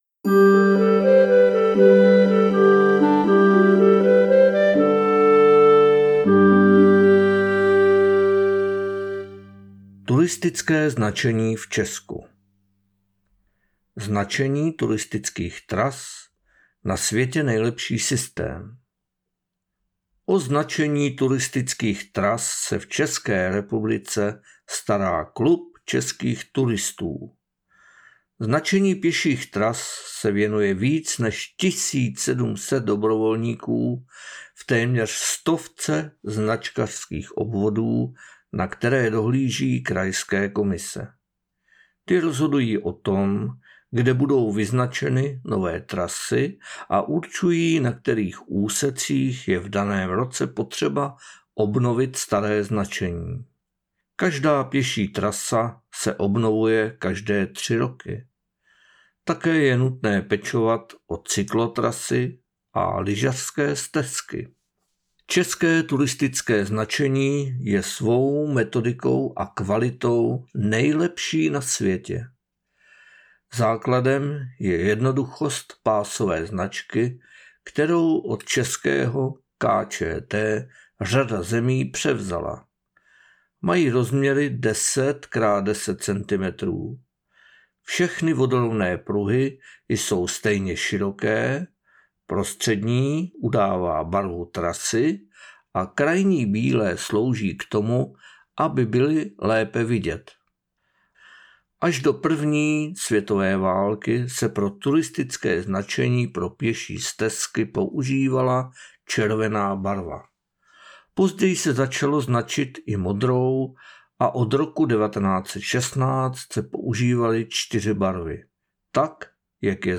SONS ČR - PAPRSEK ČERVENEC 2023 NAČTENÝ